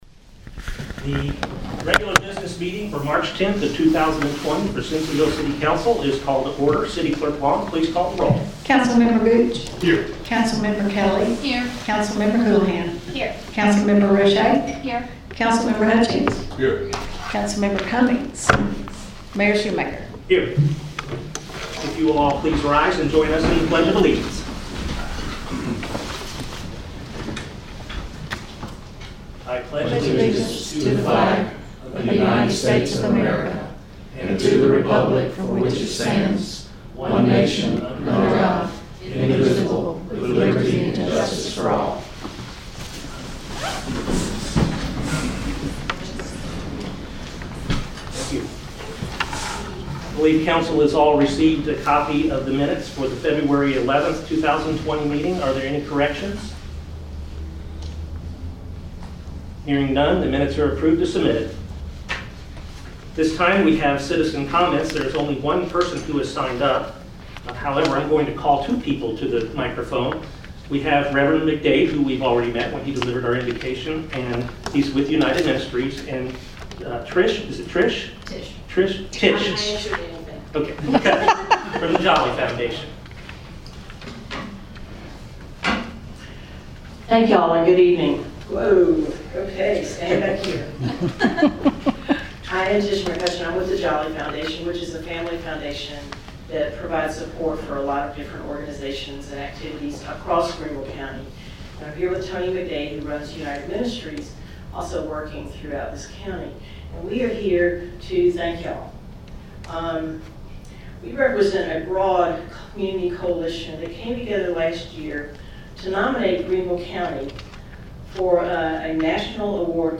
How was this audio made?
City Council Business Meeting